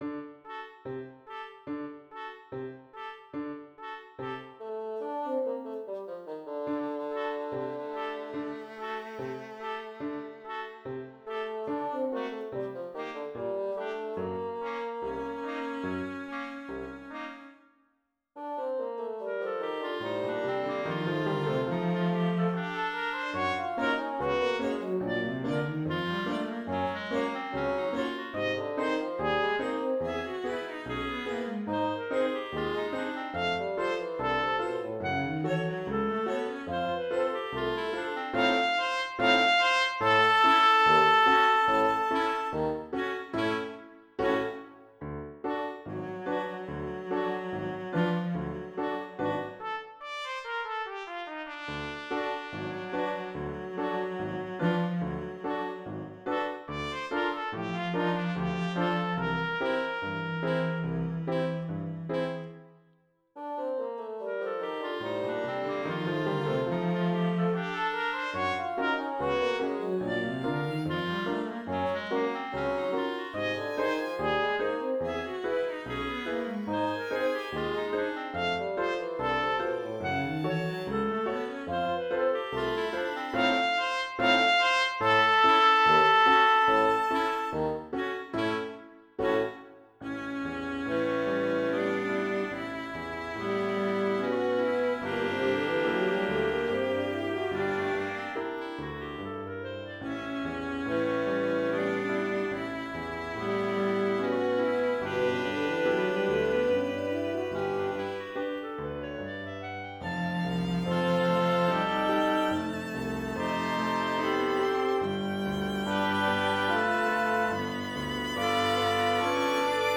Op.28 pour sextuor avec piano (Trompette, Clarinette, basson, violon, violoncelle et piano)
Maquette audio